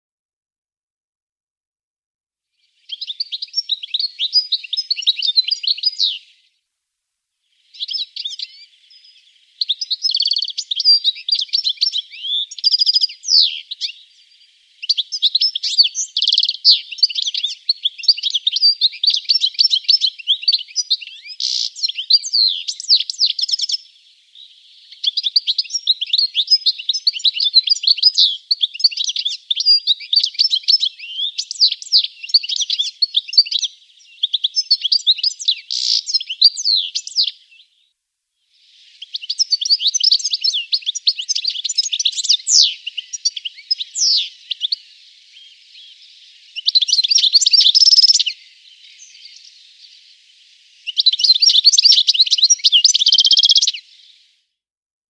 2.32 Distelfink (Stieglitz) und
Zeisige (Erlenzeisig und Birkenzeisig)
Dieser Zeisig ist ein munterer Geselle und eifriger Sänger.